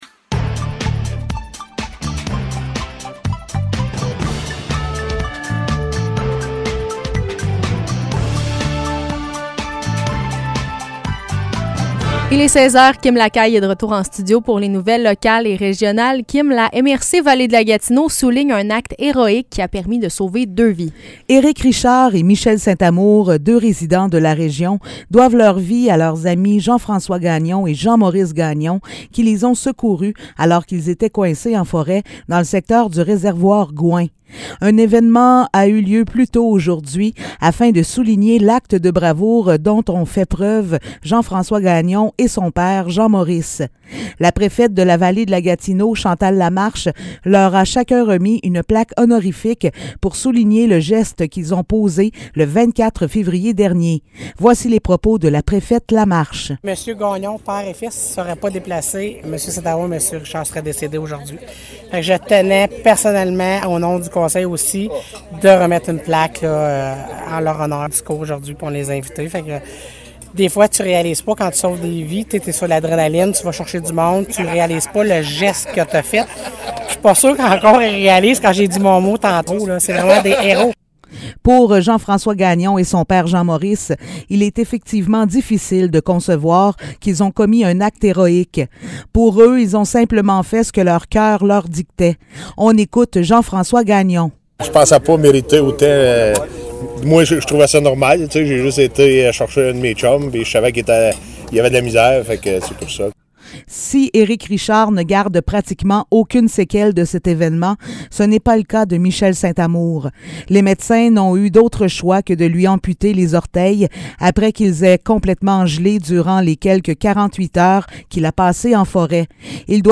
Nouvelles locales - 12 mai 2022 - 16 h